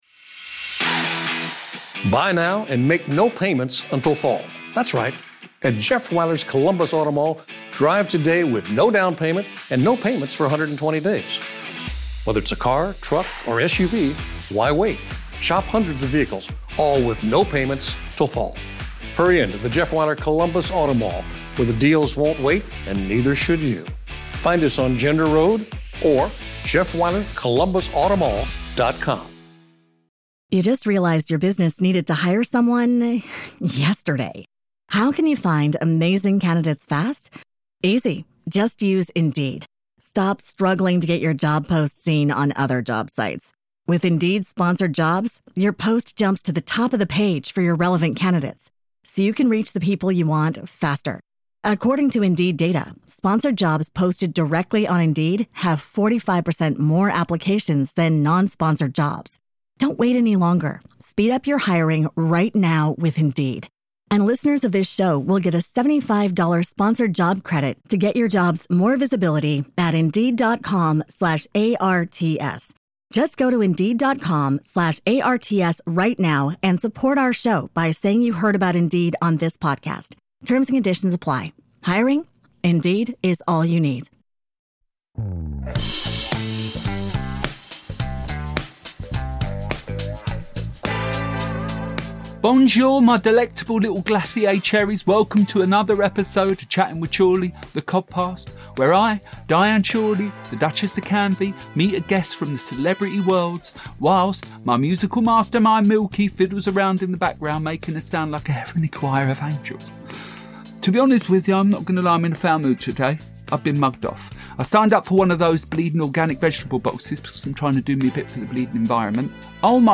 while they chat weekly to beautiful guests each week and ad-lib a unique song for them at the end of the show!